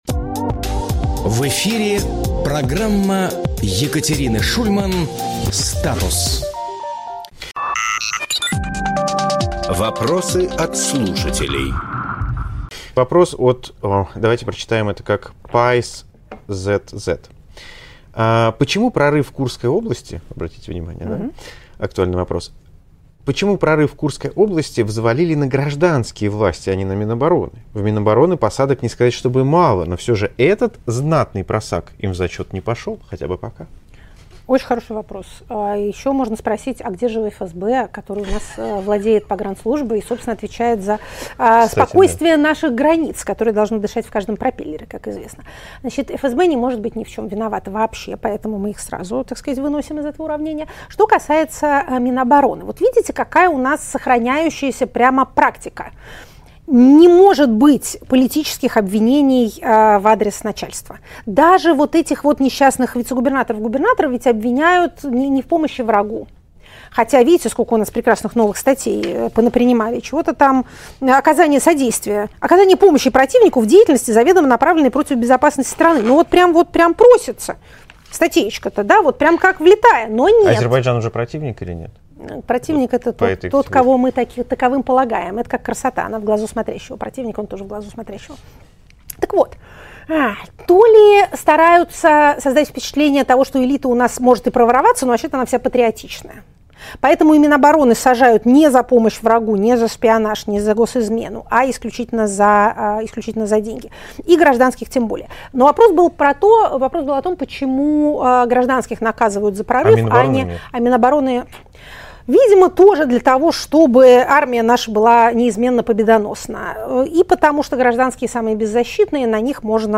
Екатерина Шульманполитолог
Фрагмент эфира от 22.07.25